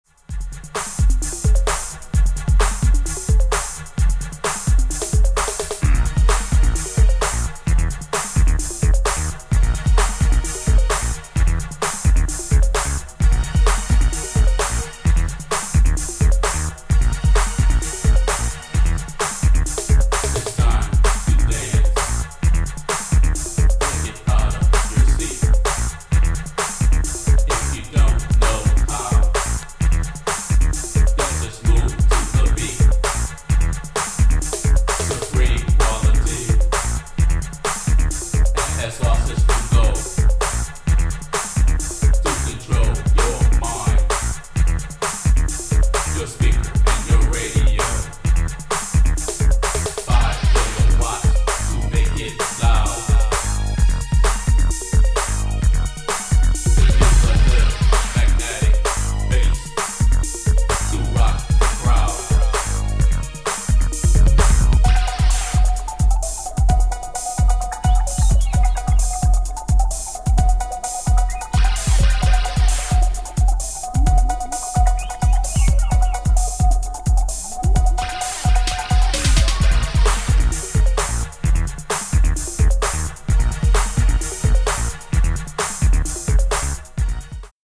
Techno-Bass
Classic Detroit Electro.